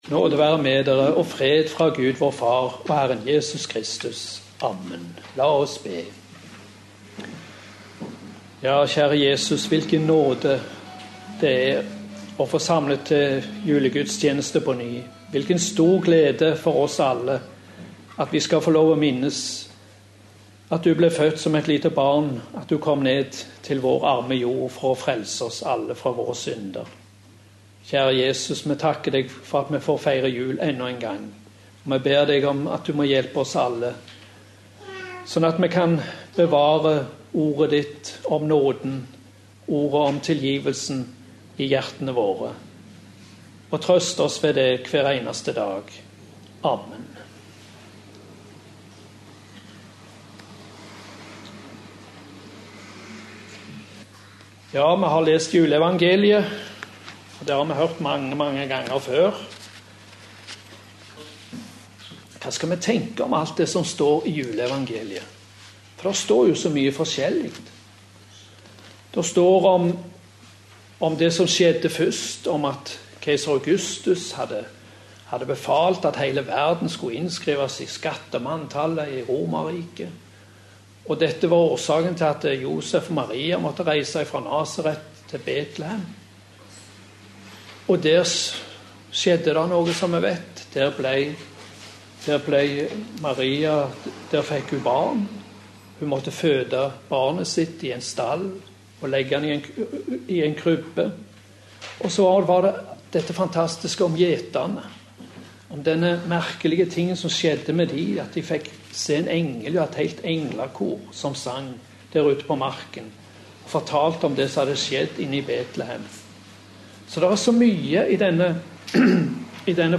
Preken på julaften